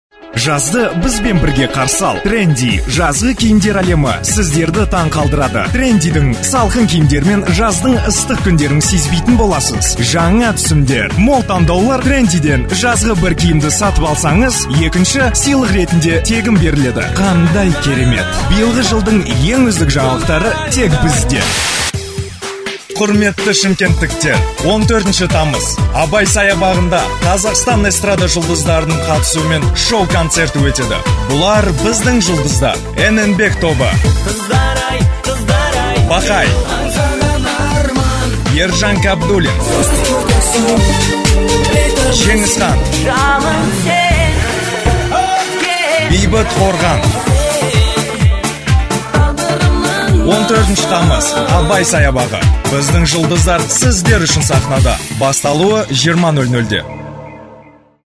Sprecher kasachisch für TV / Rundfunk /Industrie.
Sprechprobe: Werbung (Muttersprache):
Professionell voice over artist from Kazakhstan .